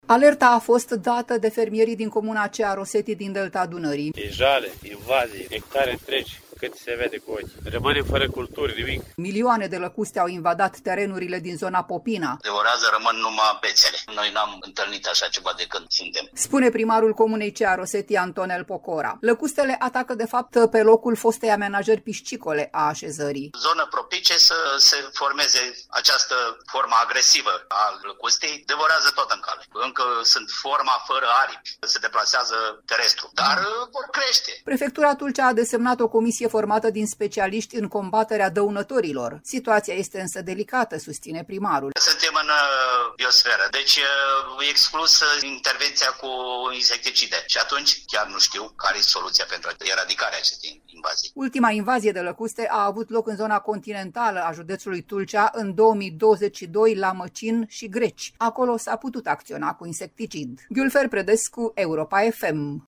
„E jale, invazie, hectare întregi, cât se vede cu ochii, rămânem fără culturi, nimic”, spune un fermier.
„Suntem în Biosferă, deci exclusă intervenția cu insecticide. Și atunci, chiar nu știu care e soluția pentru eradicarea acestei invazii”, a declarat primarul Antonel Pocora.